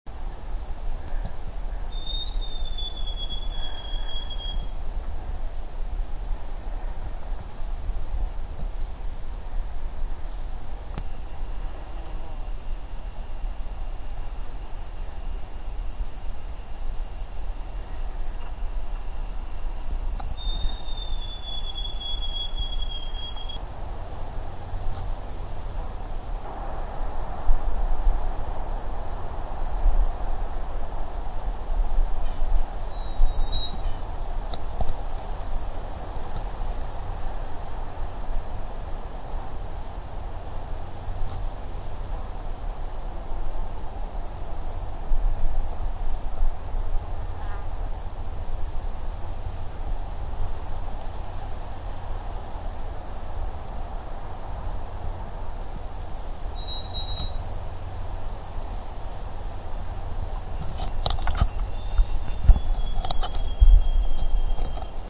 White-throated Sparrow
Notice long pauses between song sequences, dueting between two sparrows each with an individual song, one ascending, the other descending.  Pauses between song sequences appears to be much longer in the quiet summit environment.
It is like a trill in slow motion.
The first sparrow sings a four part descending song comprised of three introductory notes sliding into the four part "peabody" trill.  The song begins at 4868 herz and descends to 3305.
The trill is a continuous sound with the notes separated by changes in volume only.  The second song of the second bird drops one note from the trill.
sparrow_white-throated_at_summit_836.wav